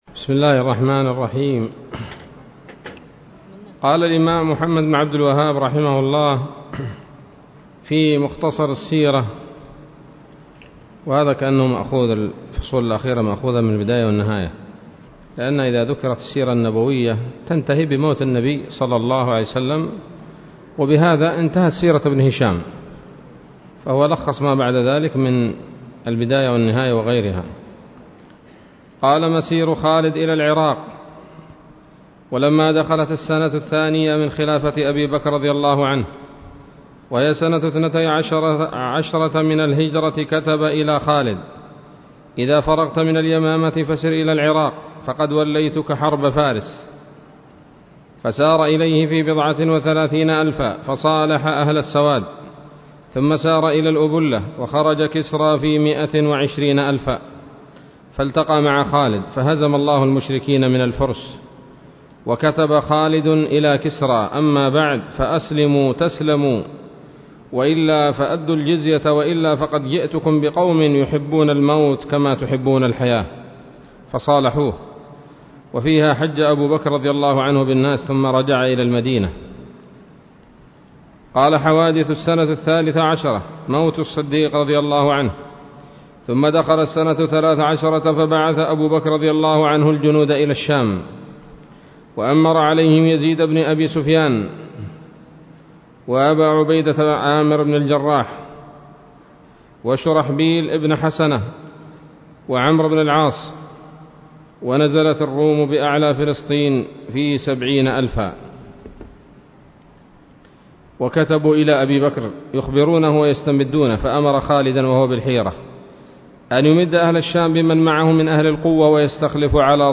الدرس الحادي والستون من مختصر سيرة الرسول ﷺ